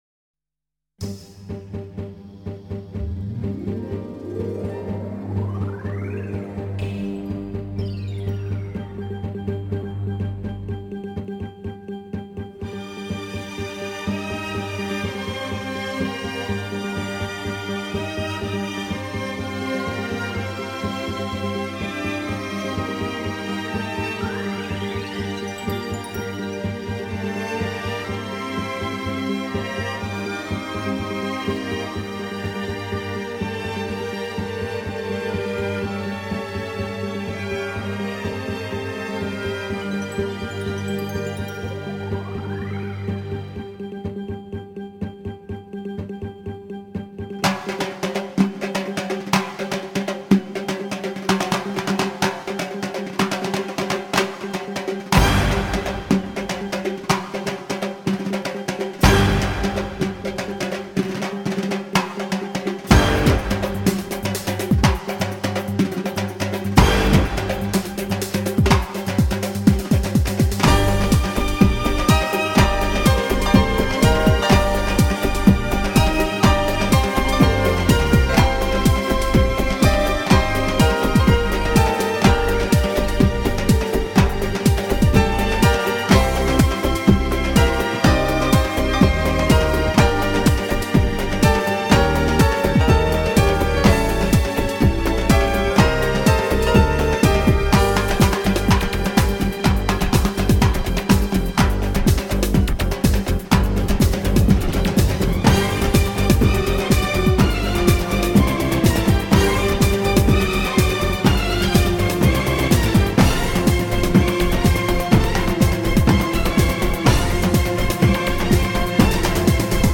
Armenian dance music